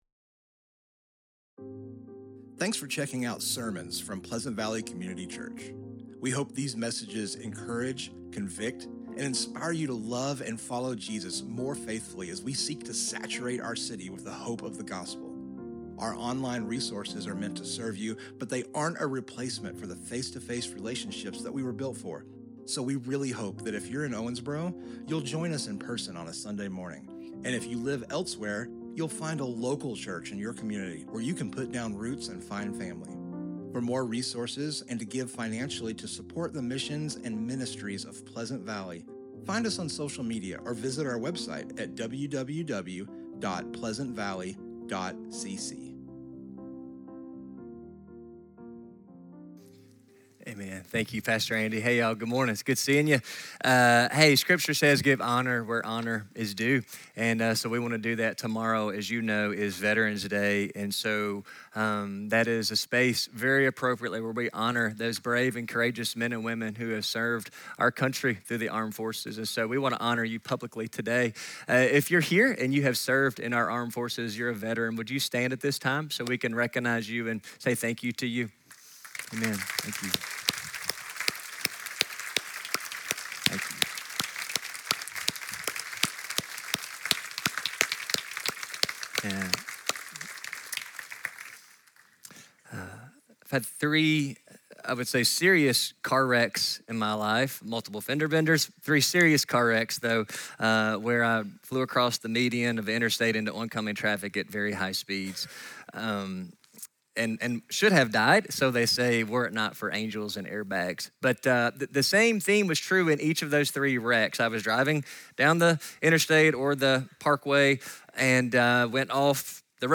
Book: Romans | Sermon Topic: